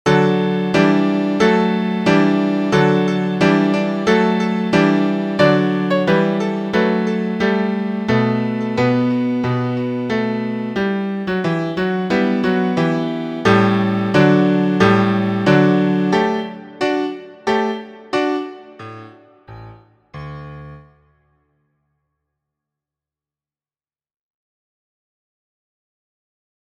Introducing the marcato (accent mark).
• Key: D Major
• Time: 4/4
• Tempo: Moderato
• Form: ABC
• Musical Elements: notes: quarter, dotted eighth, eighth, sixteenth; rest: whole; dynamics: forte, mezzo forte, decrescendo, crescendo, accent marks (marcato)